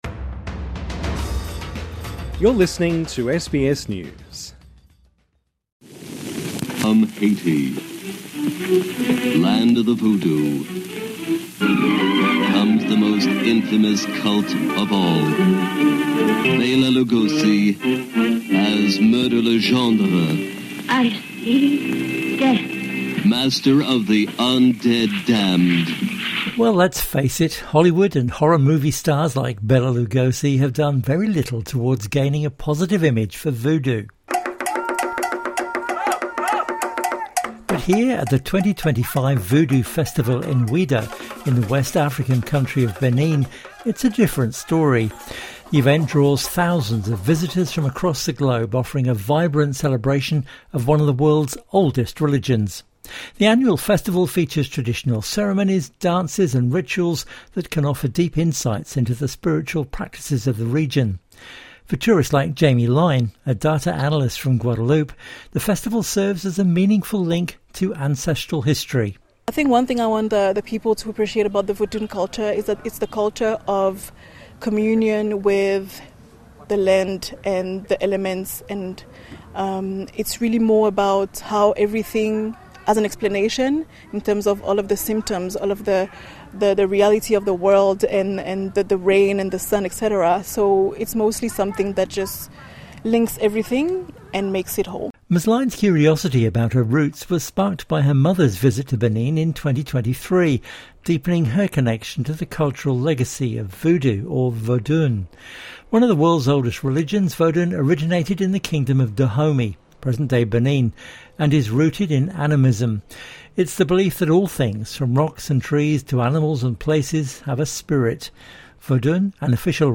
But here at the 2025 Voodoo Festival in Ouidah in the West African country of Benin it's a different story.